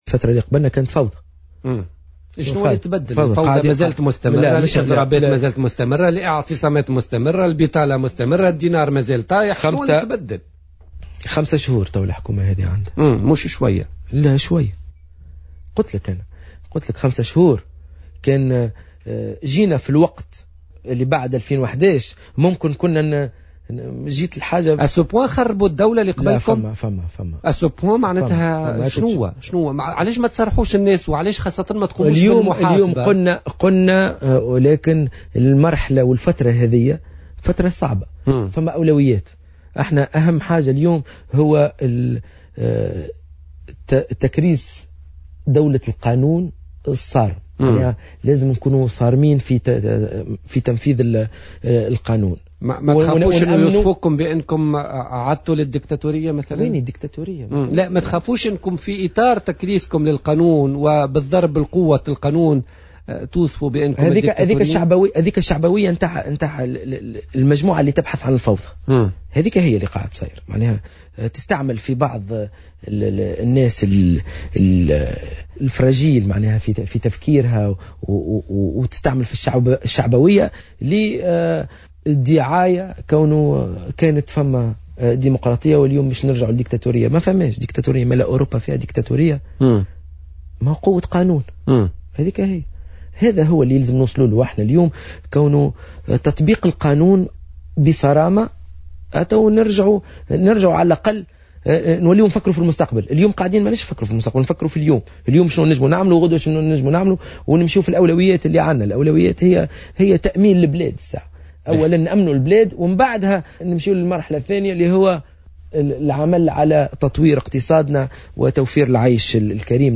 وأكد لـ"جوهرة أف أم" في برنامج "بوليتيكا" انه يرفض أن يكون هناك وزراء بالحكومة من دون خلفية حزبية وسياسية.